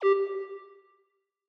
Longhorn 2000 - Ding.wav